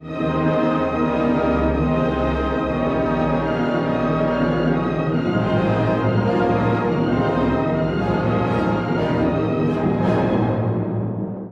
そしてコーダでは、金管楽器が高らかにファンファーレを奏でます。